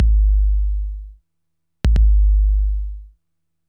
bass01.wav